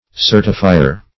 Certifier \Cer"ti*fi`er\, n. One who certifies or assures.